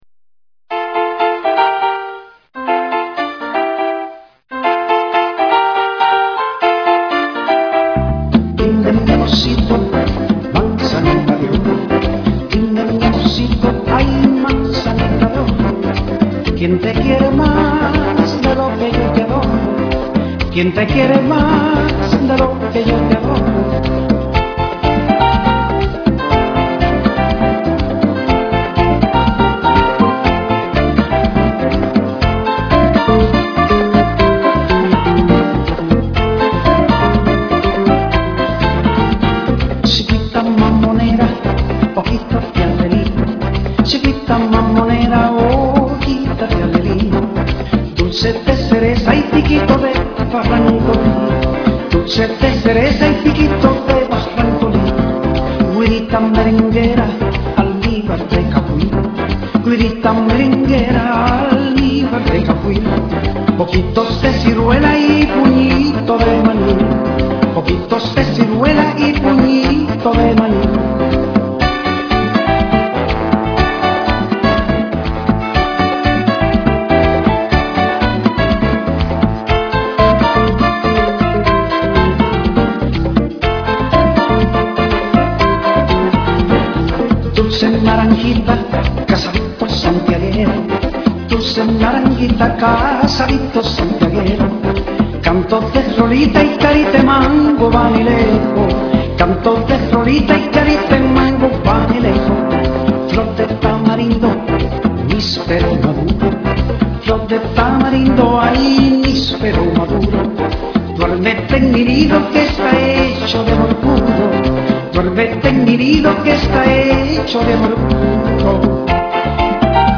Pambiche